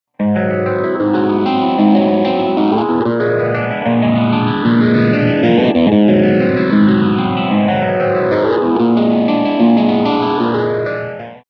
Roland GP-16